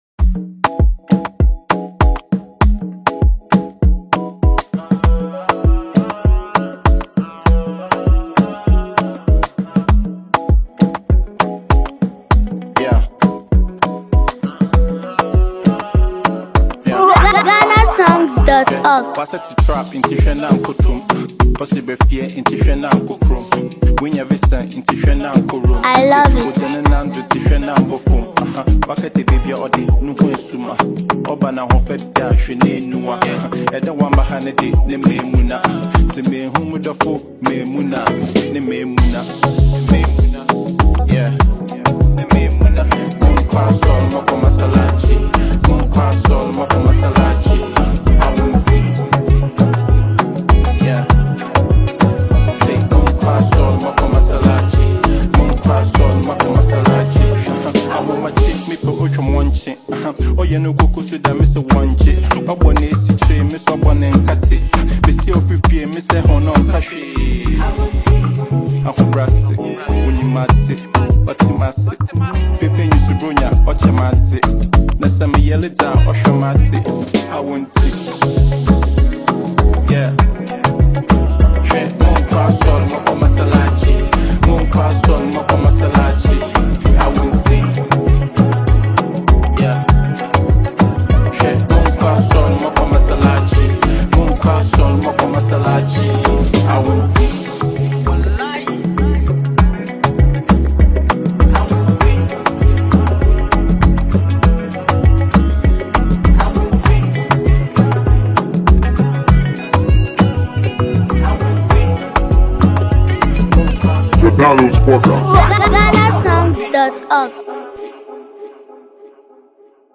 blends creativity, rhythm, and catchy melodies.
modern Ghanaian rap and Afro-fusion sounds